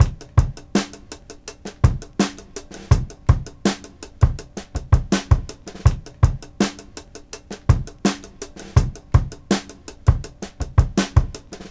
• 82 Bpm Drum Beat E Key.wav
Free breakbeat sample - kick tuned to the E note. Loudest frequency: 1011Hz
82-bpm-drum-beat-e-key-rwD.wav